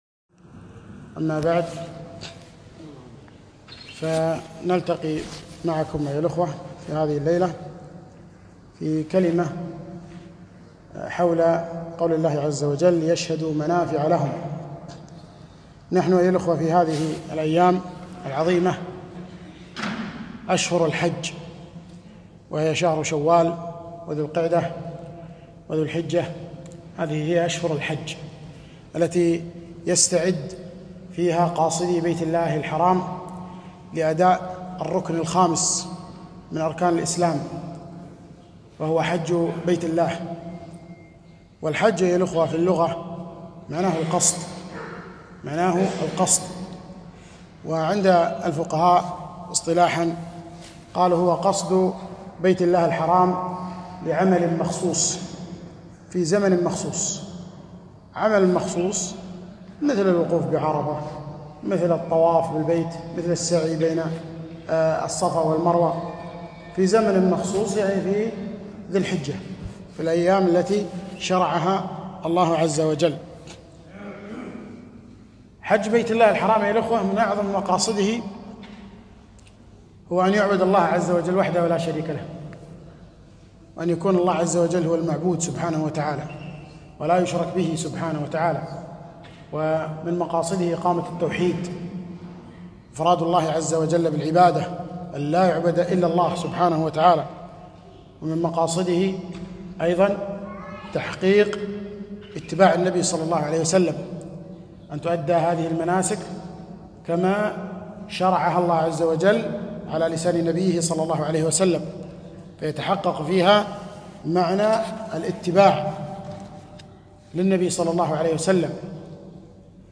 محاضرة - ليشهـدوا منافـع لـهم